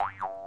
颚式竖琴" 7
描述：24位颚式竖琴录音的单次拍摄。是用鼓采样器进行排序的理想选择
标签： boingy 竖琴 下巴
声道立体声